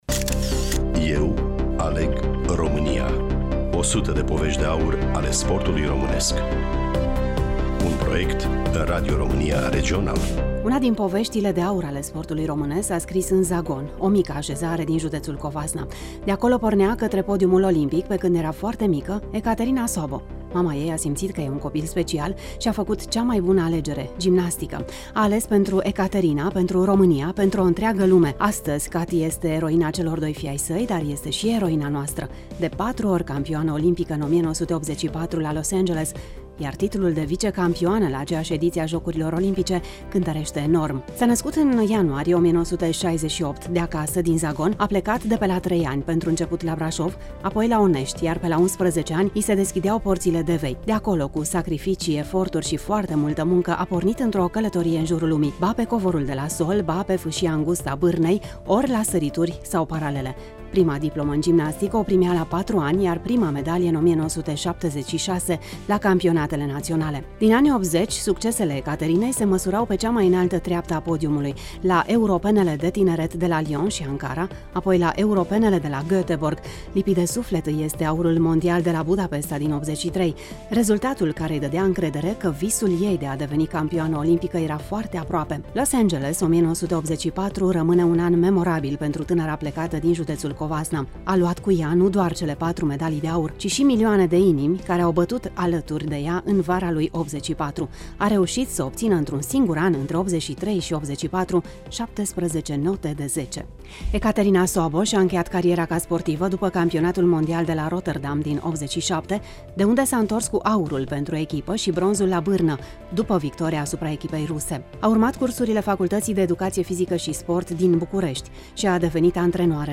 Sunt amintiri pe care, cu ani în urmă, Kati ni le-a împărtășit chiar în casa natală de la Zagon, amintiri dragi păstrate în arhiva de aur a Radio Tg.Mureș.